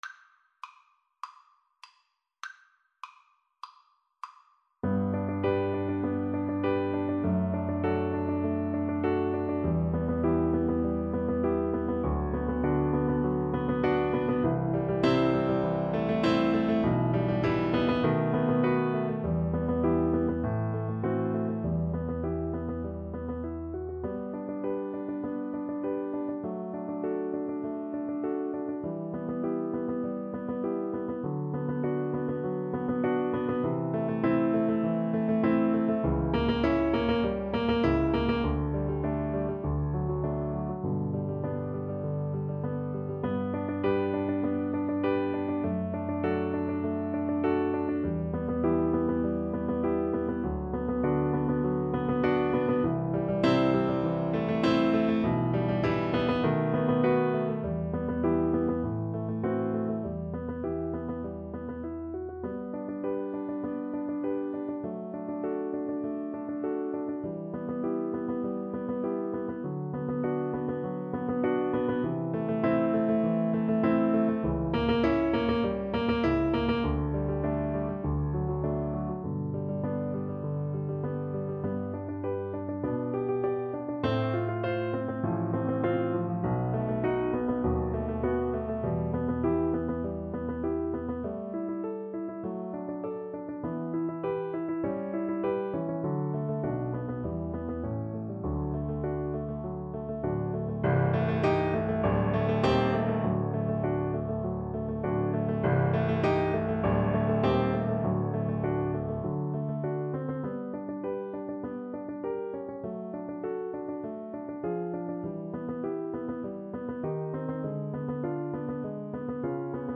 Allegro moderato (View more music marked Allegro)
Classical (View more Classical Cello Music)